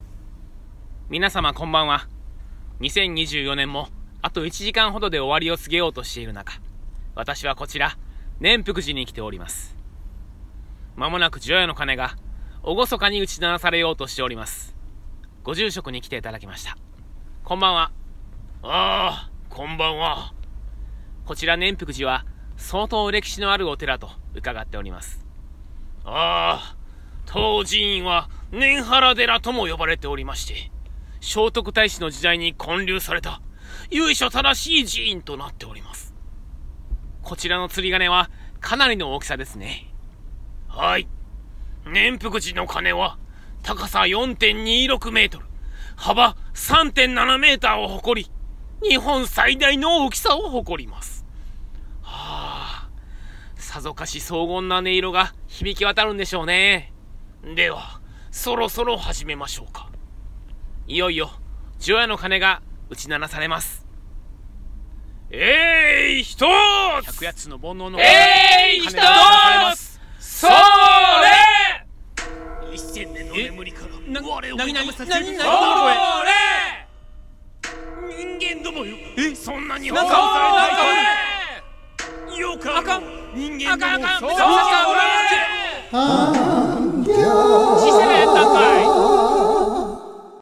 完成版声劇「ゆく年くる年🔔④」